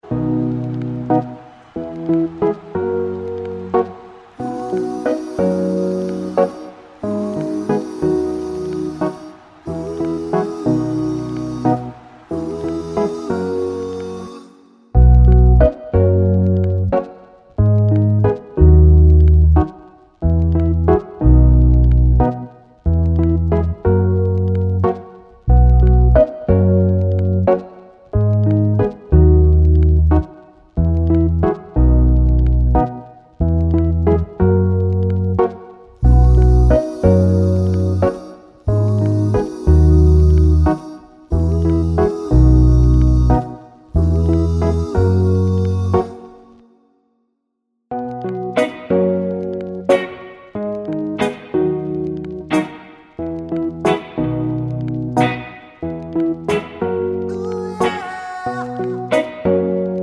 no Backing Vocals Pop (2010s) 3:06 Buy £1.50